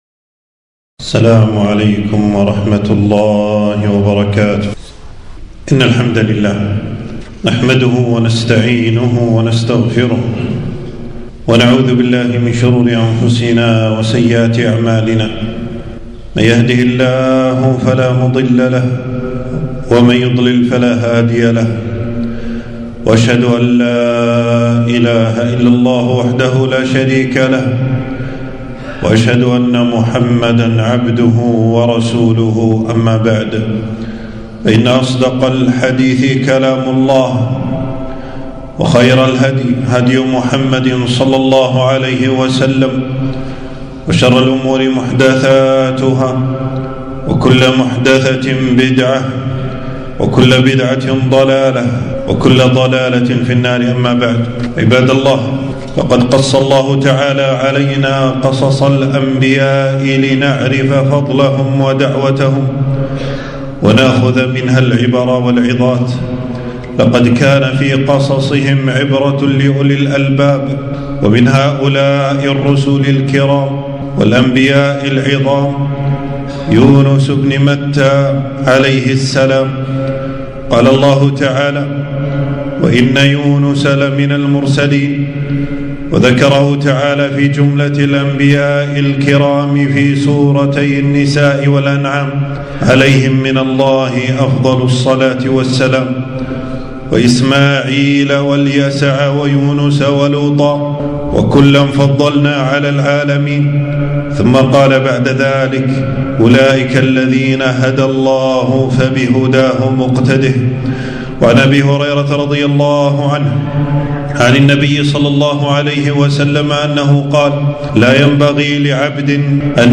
خطبة - يونس بن متى عليه السلام